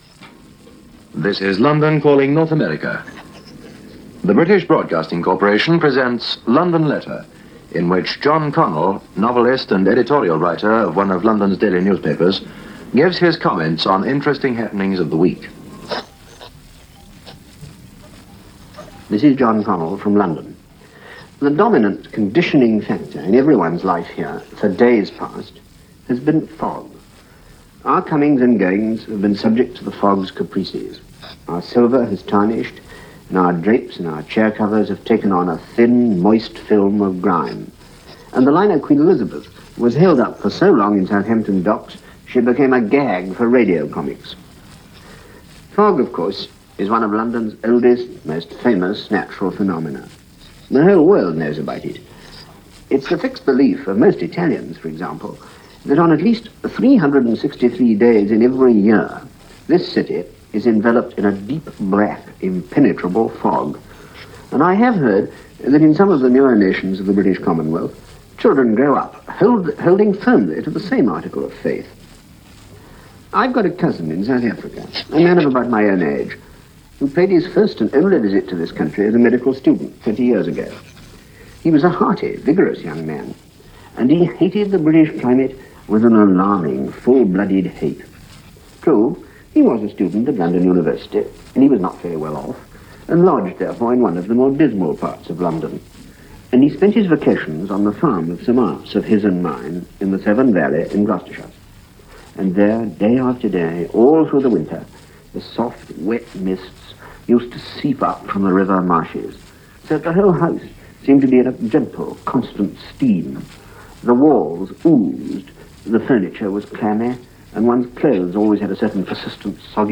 Among the many and varied programs the BBC beamed to the U.S. by way of the World Service, as well as The Transcription Service, were these informational programs, talking about life in Britain after the war – the history and background of people, places and events – a lot of human interest.